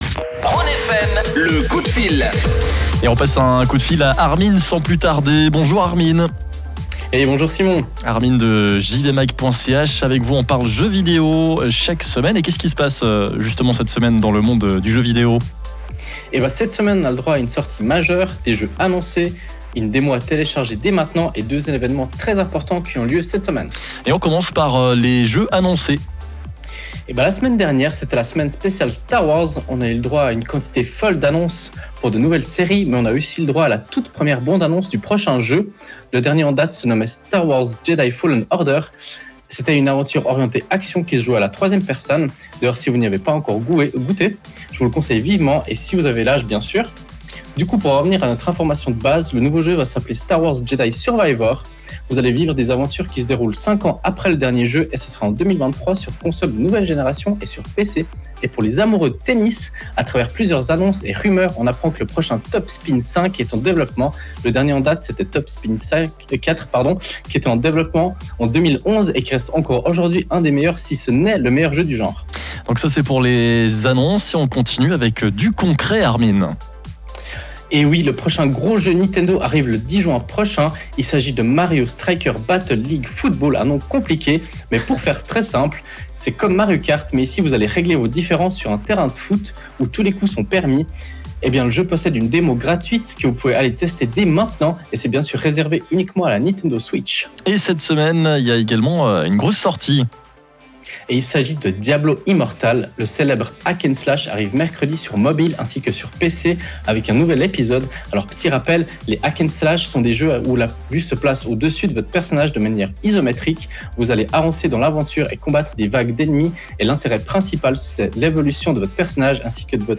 Vous pouvez réécouter le direct via le flux qui se trouve juste en dessus.